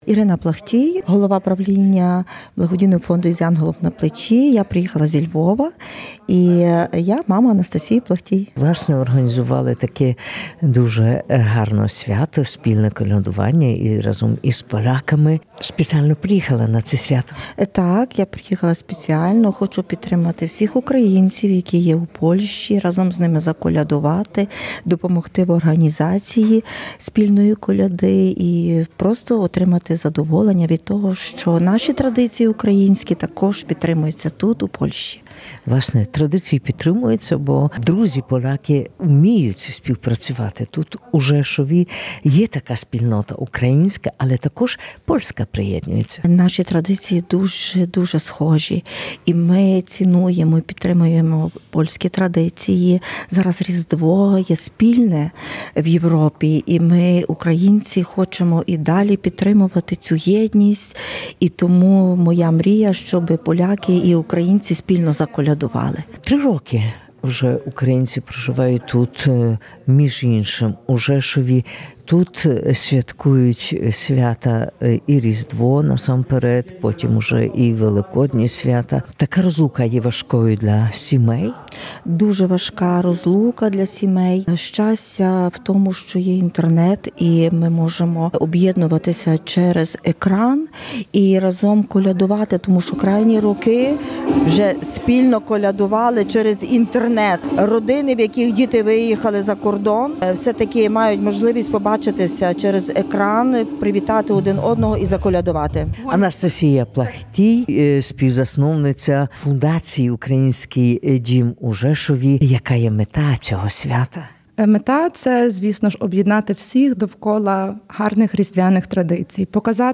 04.01-SKRYNIA-KOLEDY.mp3